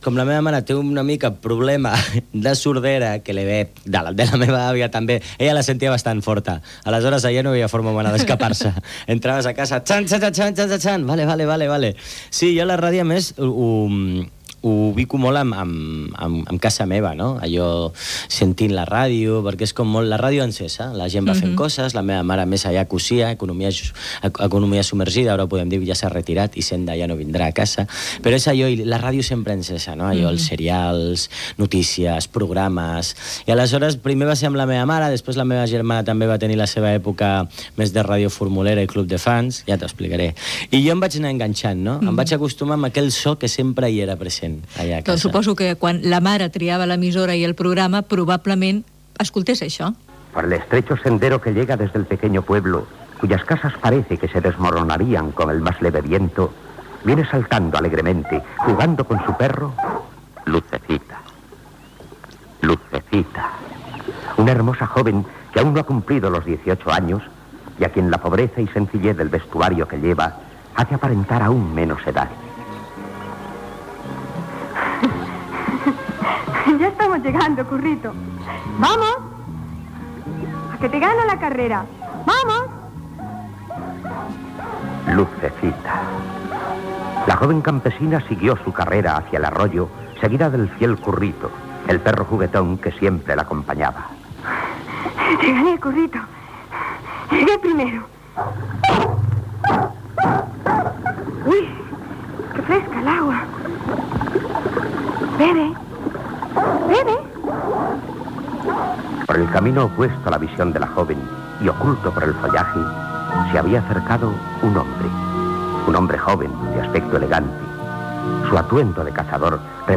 Records de la ràdio que escoltava l'actor José Corbacho. Fragment del serial radiofònic "Lucecita".